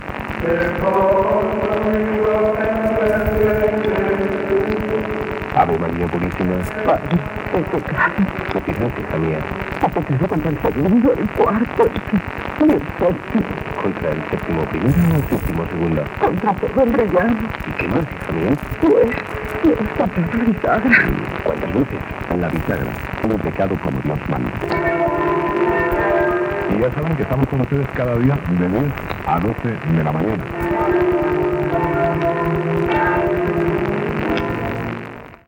Promoció Presentador/a Sardà, Xavier